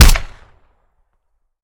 weap_delta_sup_plr_01.ogg